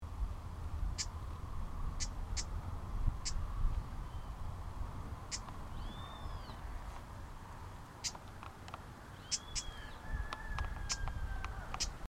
Llamada. Construyendo el nido en un juncal.
Nome em Inglês: Wren-like Rushbird
Detalhada localização: Área Protegida Municipal Laguna Cacique Chiquichano
Condição: Selvagem
Junquero--llamada.mp3